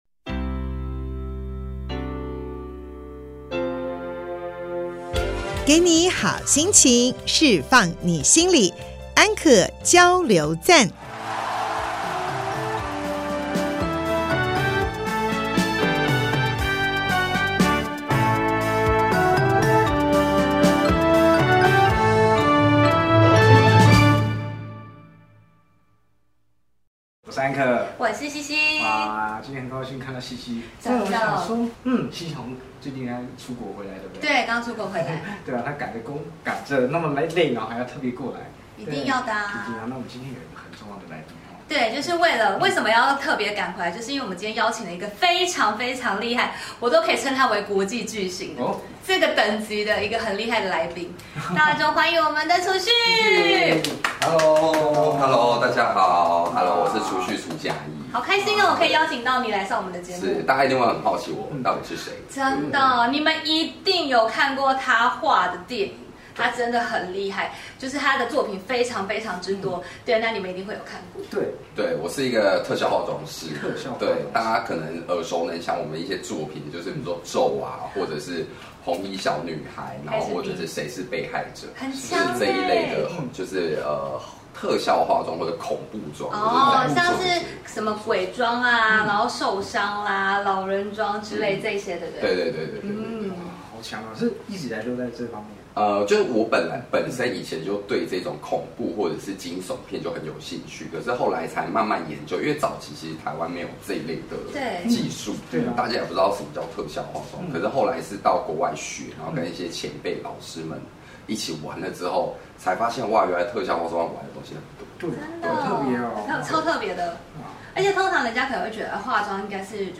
節目裡有生活點滴的分享、各界專業人物的心靈層面探析及人物專訪，比傳統心理節目多加了歷史人物與音樂知識穿插，更為生動有趣，陪伴您度過深夜時光，帶來一週飽滿的智慧與正能量。